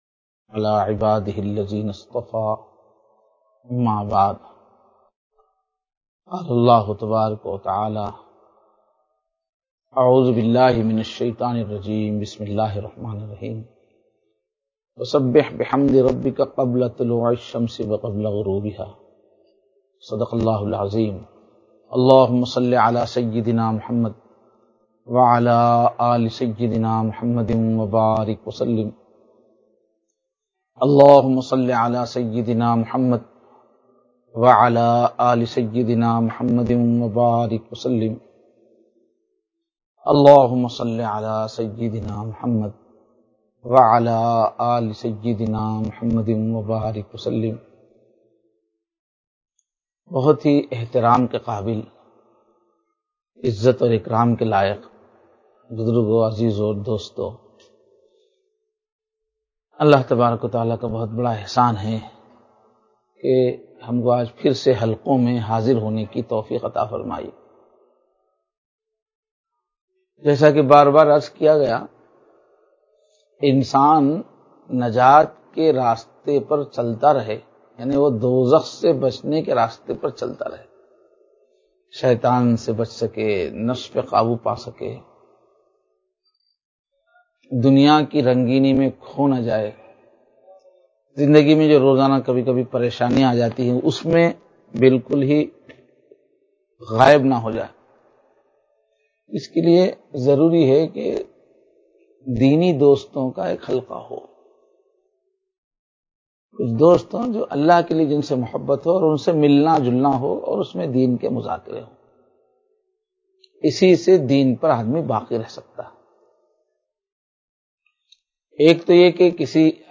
Live Online Bayan